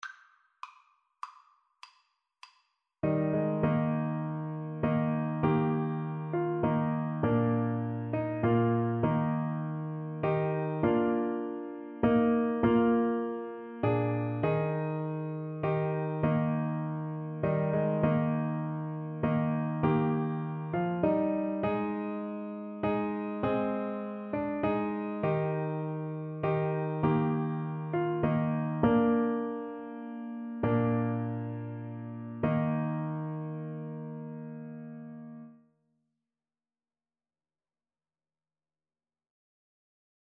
6/4 (View more 6/4 Music)